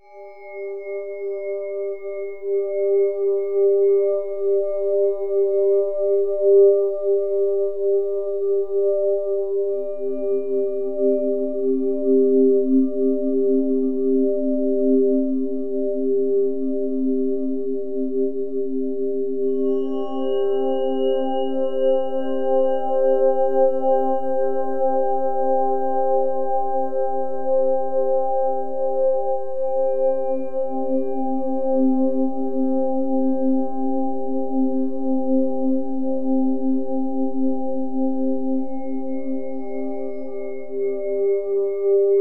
5THS VIBRA.wav